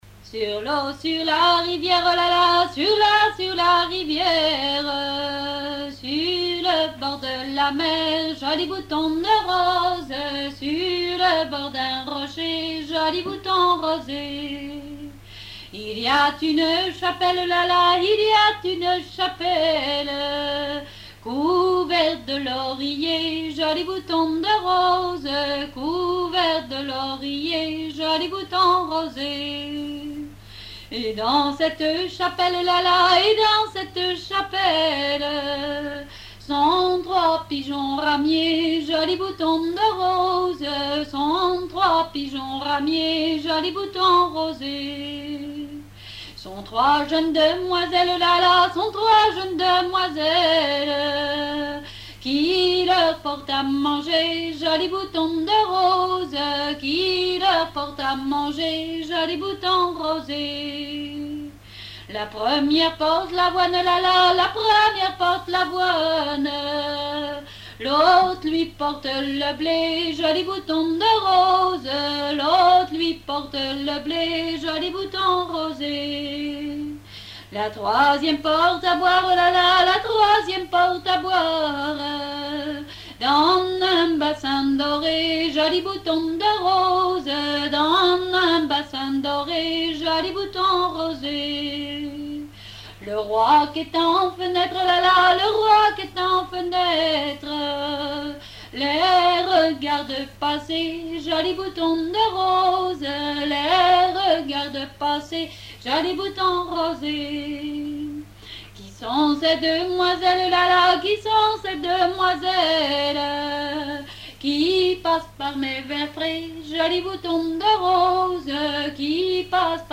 Genre laisse
répertoire de chansons traditionnelles
Pièce musicale inédite